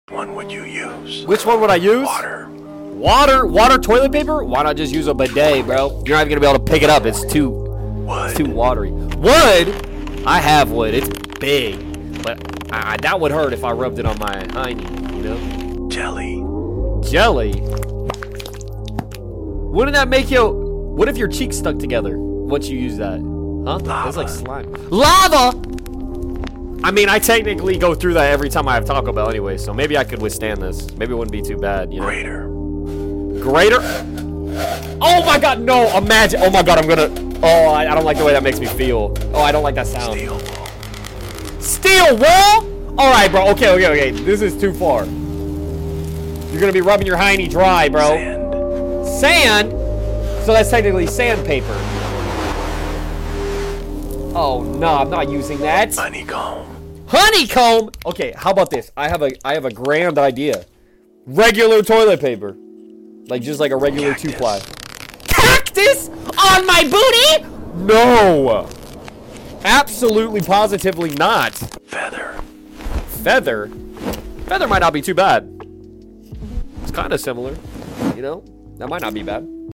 toilet paper asmr